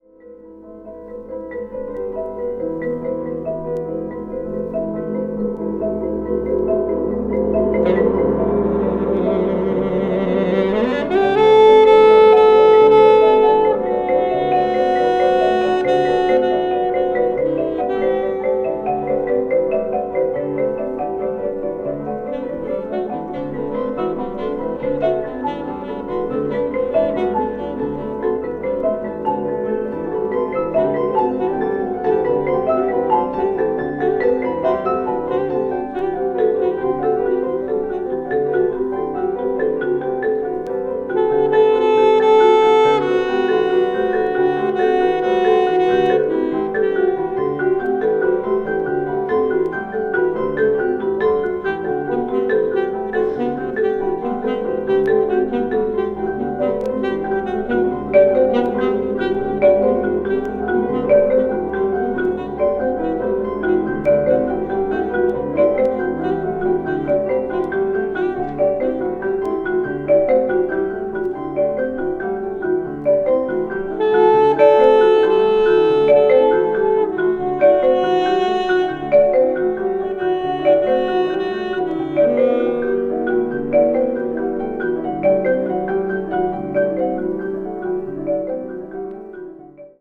media : VG+/VG+(わずかにチリノイズが入る箇所あり,再生音に影響ない薄い擦れあり)